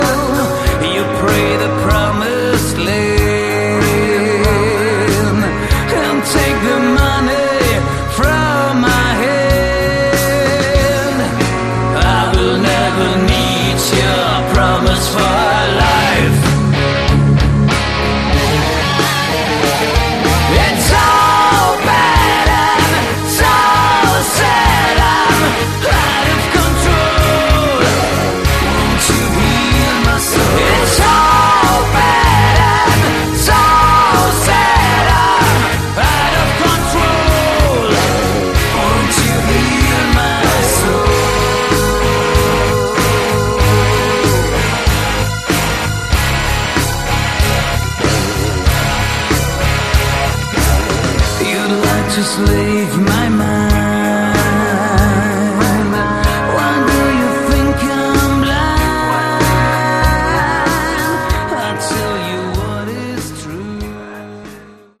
Category: Hard Rock
Like many European bands, the vocals are heavily accented.